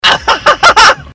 Laugh 13